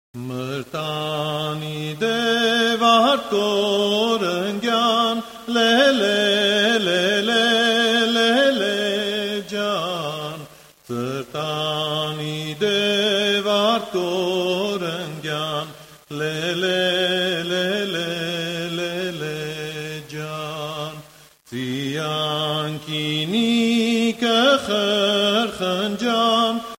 - Traditional songs of Armenia - FM 50033
Mur Tan Idev - Behind our house (ring dance) - Shatak
voc